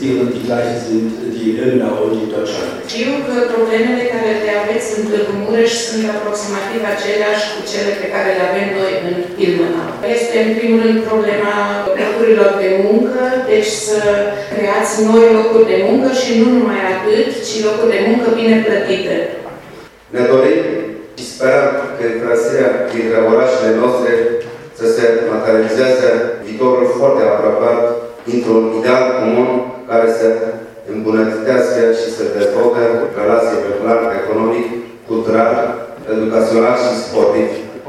Dorin Florea le-a vorbit despre posibilitățile de dezvoltare ale Municipiului și le-a cerut sprijinul pentru mai mari șanse de realizare: